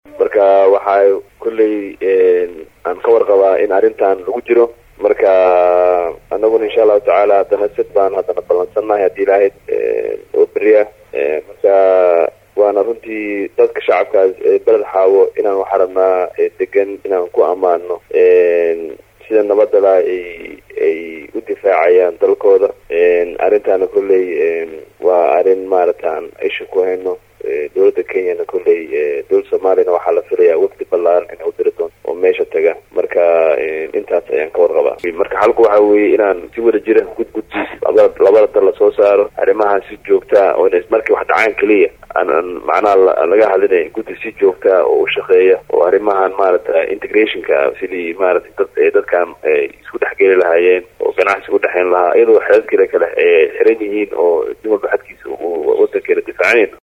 Hoos Ka Dhageyso Codka Xildhibaan Sadiiq Warfaa.